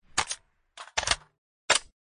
reload.mp3